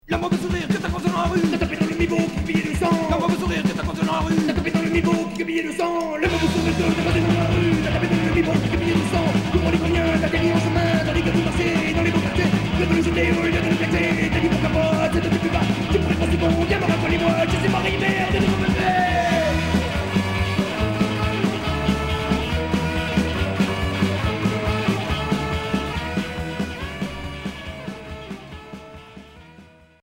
Oi